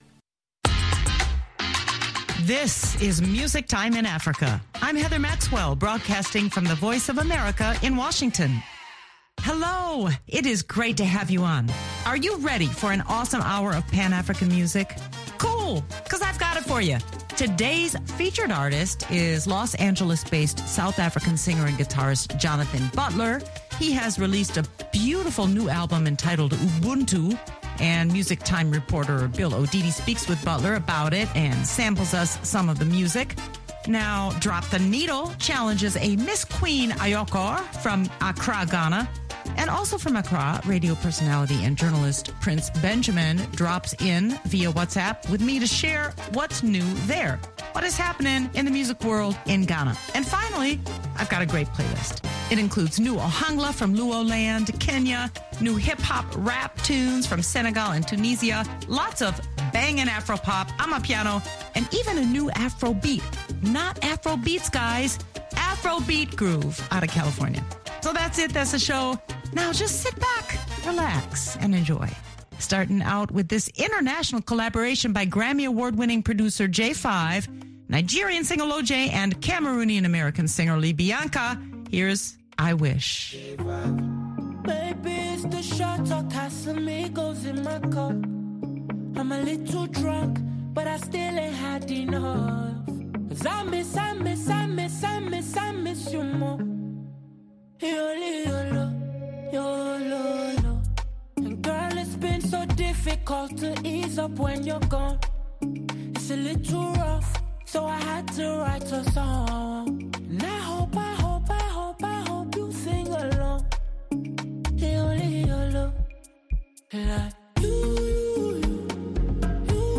All this plus and an exciting playlist of the best pan-African music.